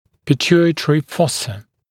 [pɪ’tjuːɪtrɪ ‘fɔsə][пи’тйу:итри ‘фосэ]гипофизарная ямка, турецкое седло